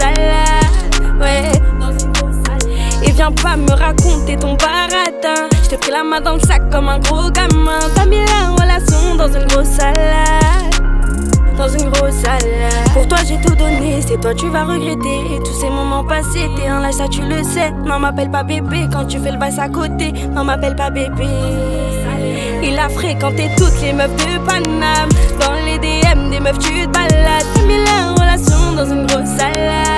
R B Soul French Pop
Жанр: Поп музыка / R&B / Соул